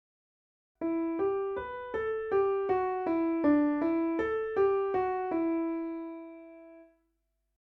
Practice finding the tonic in a minor key
Question 2: D
Leading tone: Question 2